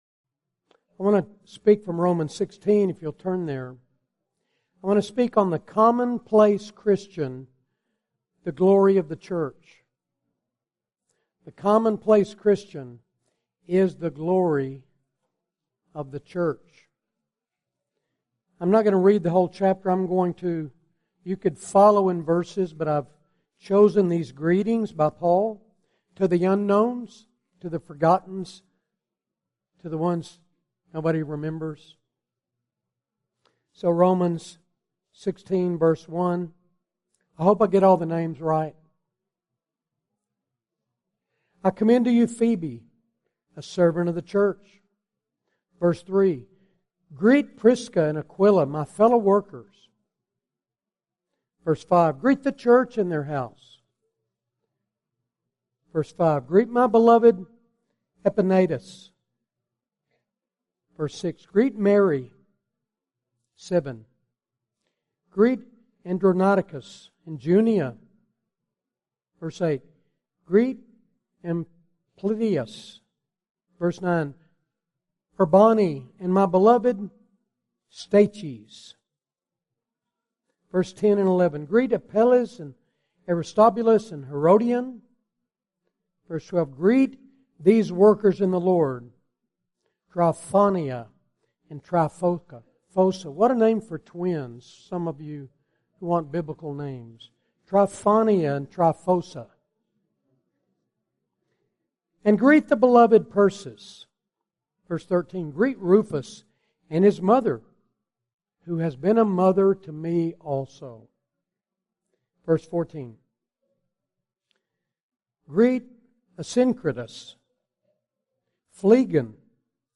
2019 Fellowship Conference | Most of us will be not be remembered after we die.